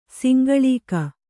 ♪ singḷīka